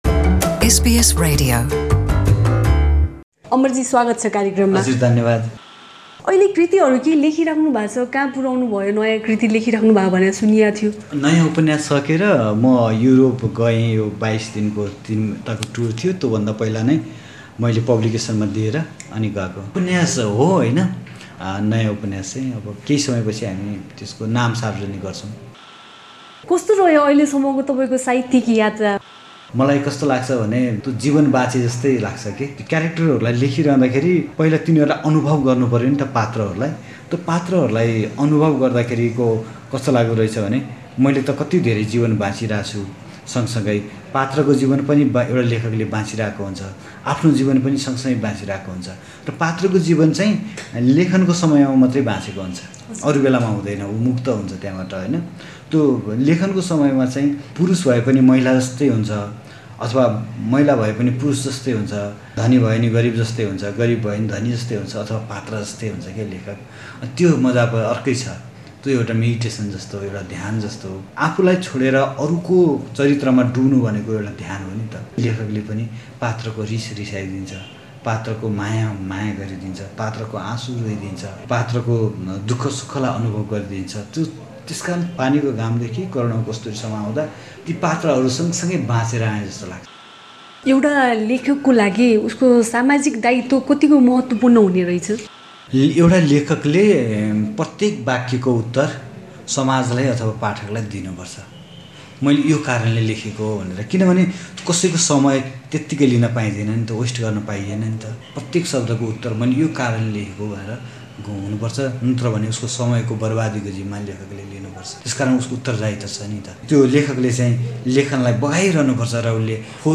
उनले सार्वजनिक हुन लागेको नयाँ कृति र साहित्यिक यात्राबारे एसबिएस नेपालीसँग गरेको कुराकानी: न्यौपानेसंगको हाम्रो पुरा कुराकानी सुन्न माथी रहेको मिडिया प्लेयरमा प्ले बटन थिच्नुहोस् १) नयाँ कृति लेखनको तयारी कहाँ पुग्यो ?